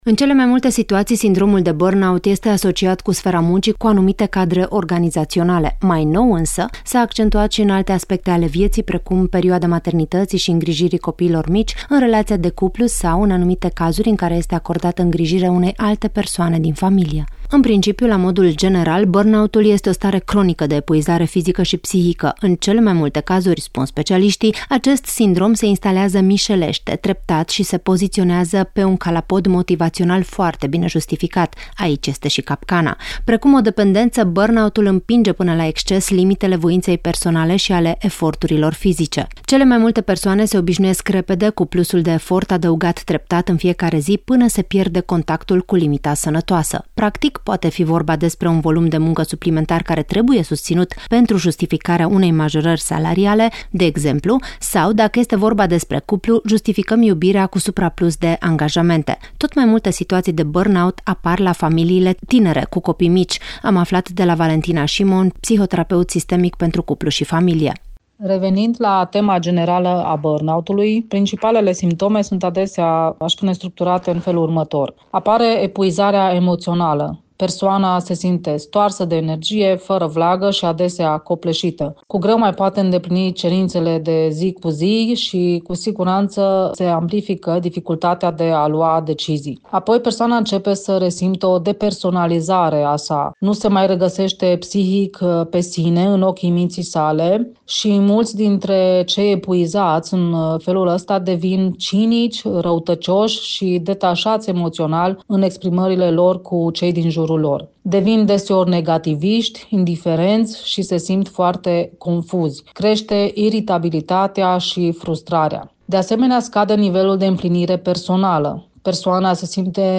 psihoterapeut sistemic pentru cuplu și familie